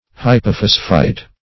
Hypophosphite \Hy`po*phos"phite\, n.
hypophosphite.mp3